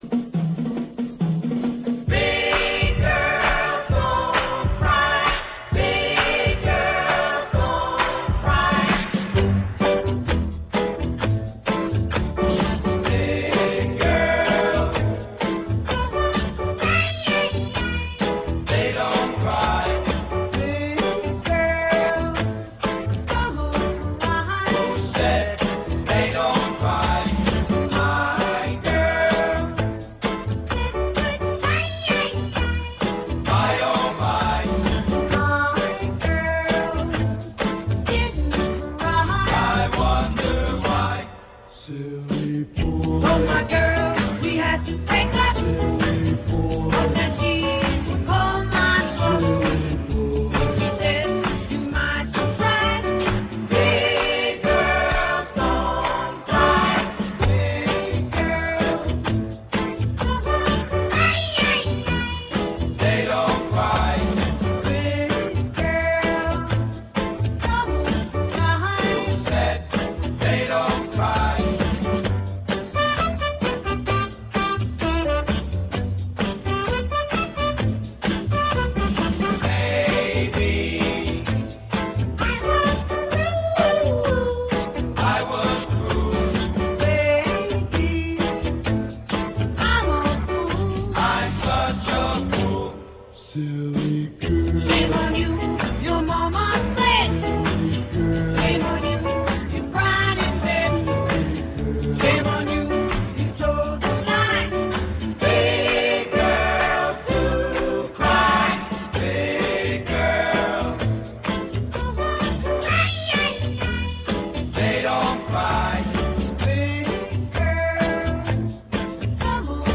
THESE SOUNDS ARE IN REALAUDIO STEREO!